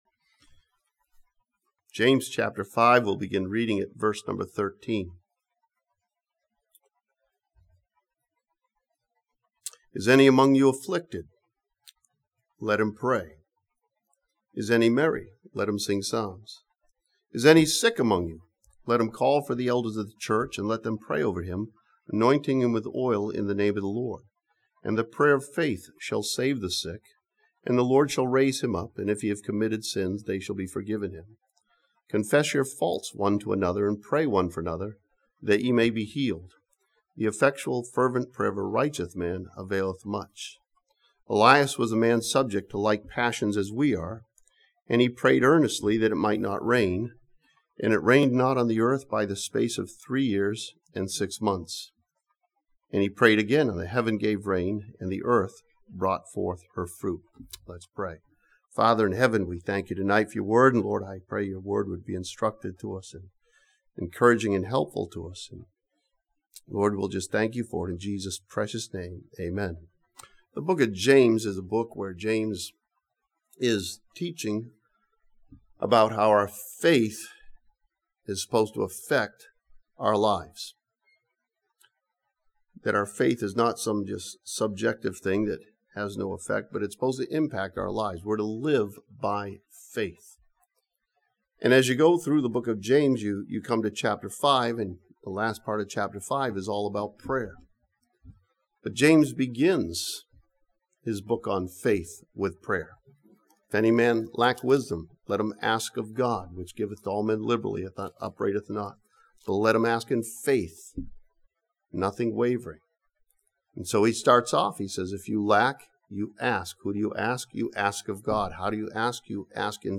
This sermon from James chapter five challenges believers to overcome three common hindances to a powerful prayer life.